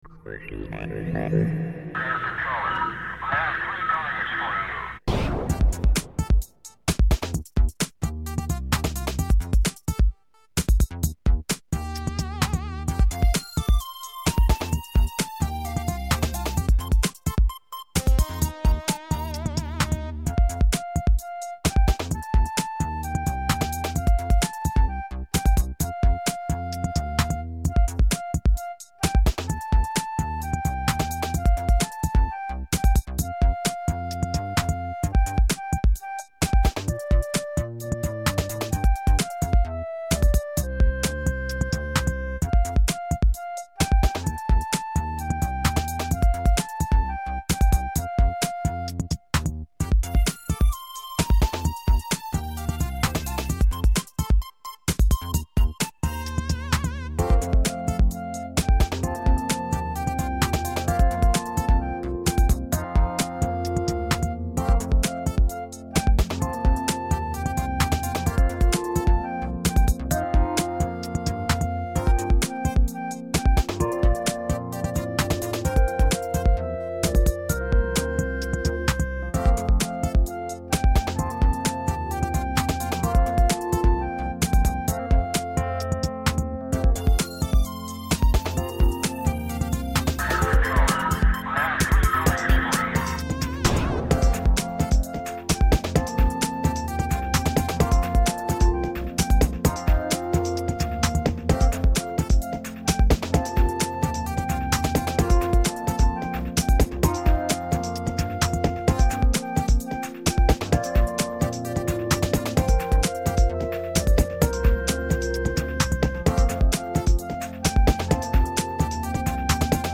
Acompanyament musical de “La llum de la lluna”